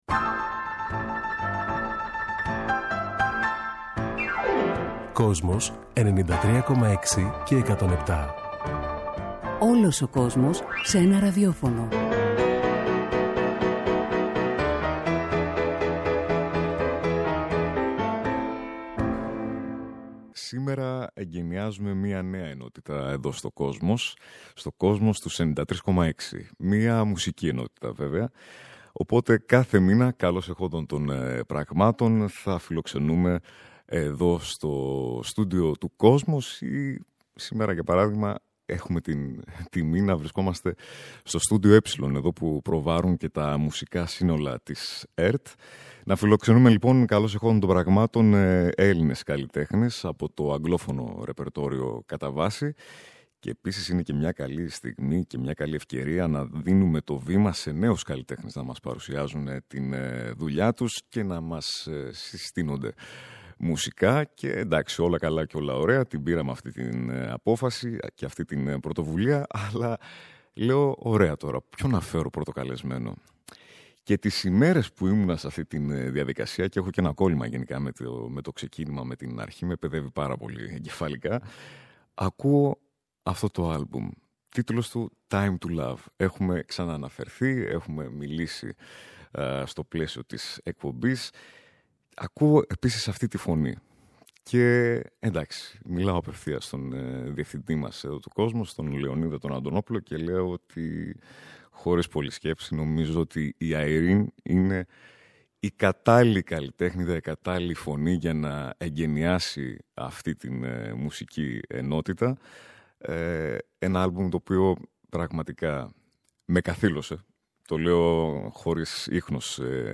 Soul, RnB και Hip Hop ανακατεύονται στα τραγούδια